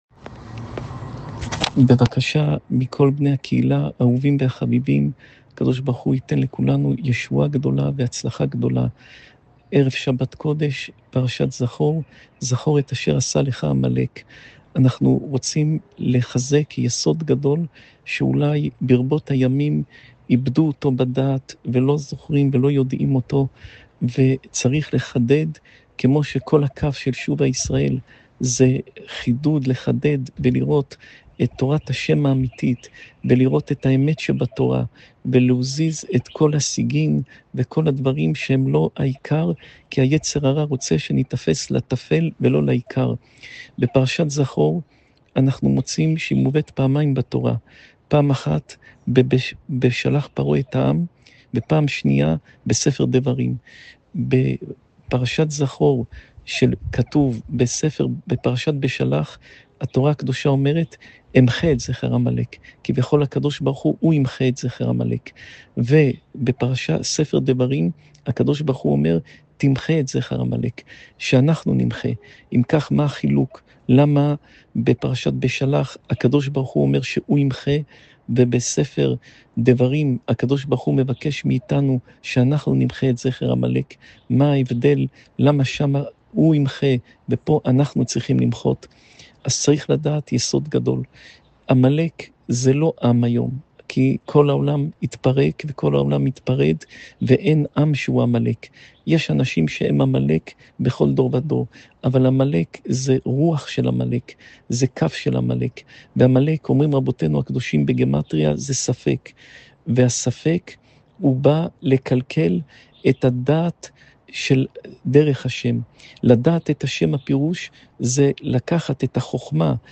שעורי תורה